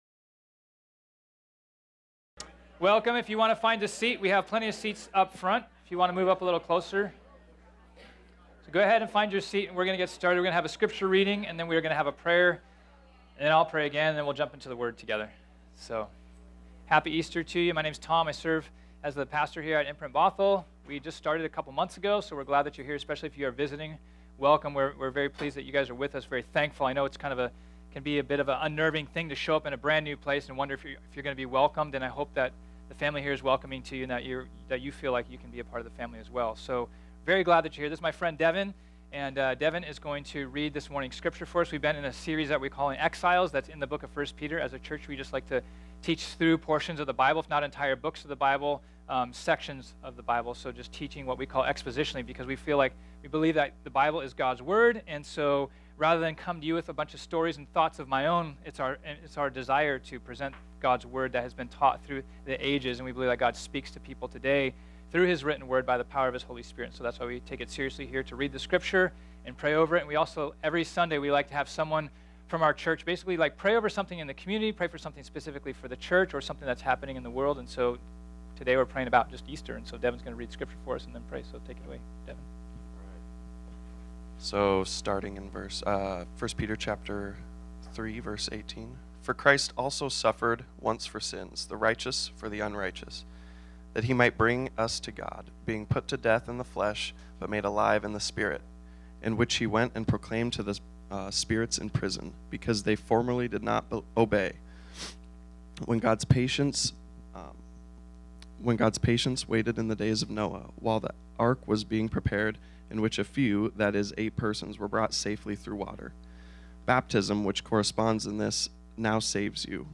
This sermon was originally preached on Sunday, April 1, 2018.